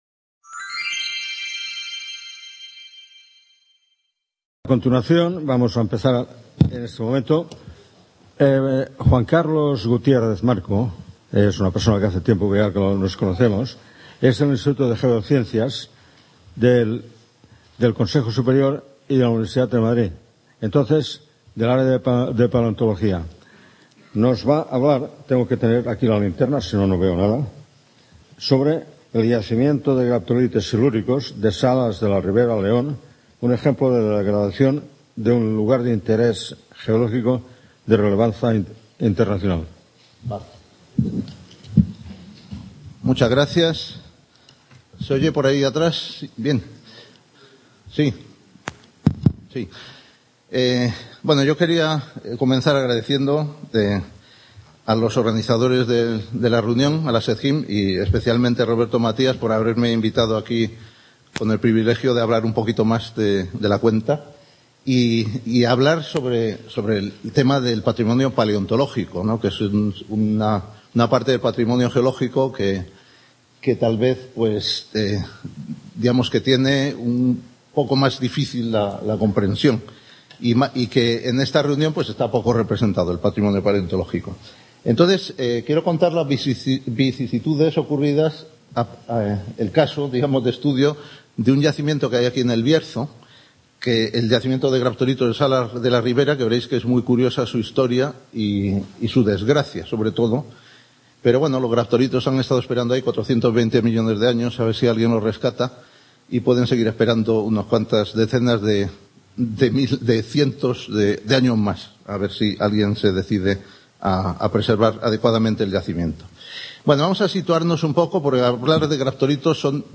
XVIII Congreso Internacional sobre Patrimonio Geológico y Minero